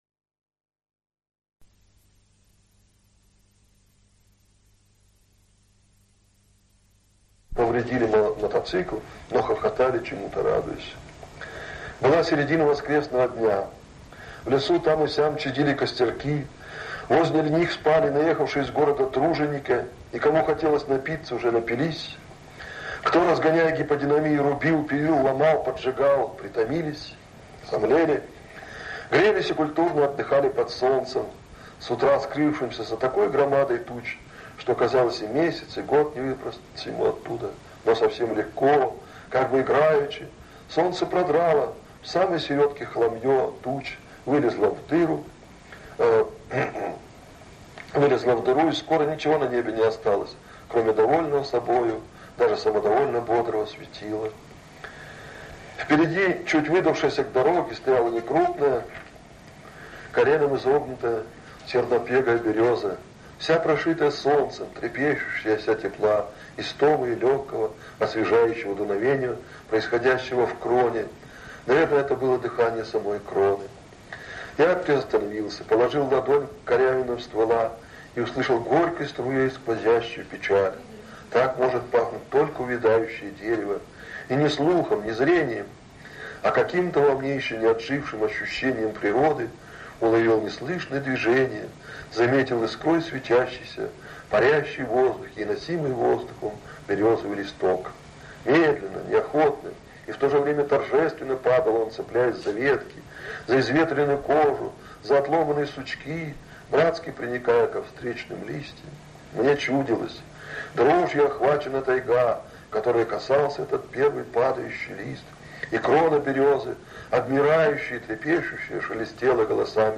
На этой аудиозаписи, относящейся к 1999 году и оцифрованной в Красноярском Кинографе в 2014 году, собрано сразу несколько…